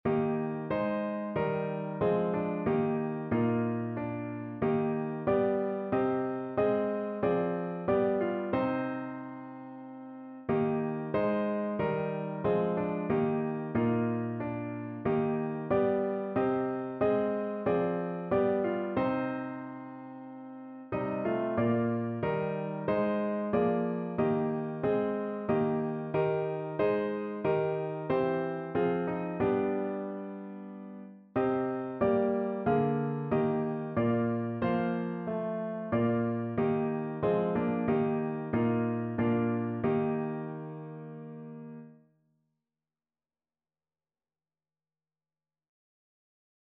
Notensatz 1 (4 Stimmen gemischt)
• gemischter Chor mit Akk.